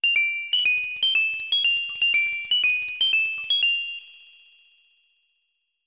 Sound effect from Super Mario RPG: Legend of the Seven Stars
Self-recorded using the debug menu
SMRPG_SFX_Doom_Reverb.mp3